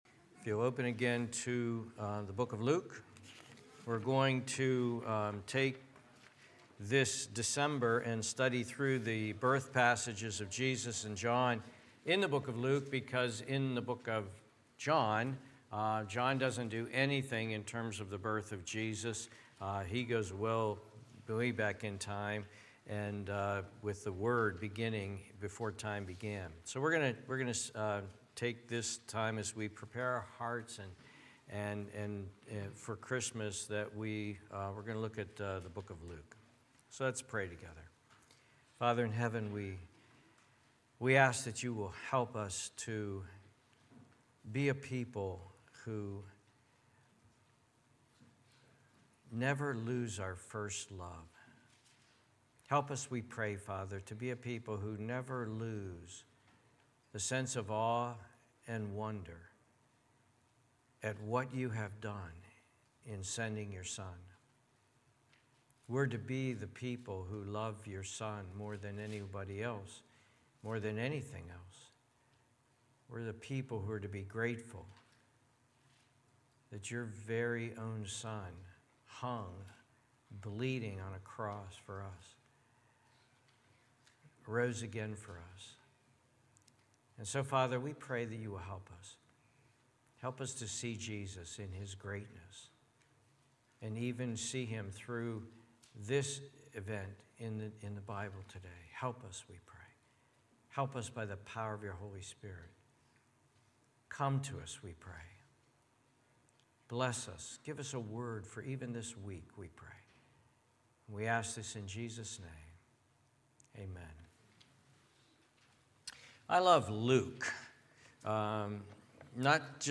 Sermon, Bible Study, and Grace School of Ministry training course audio recordings from Crossroads Christian Fellowship Church of Greenville, PA.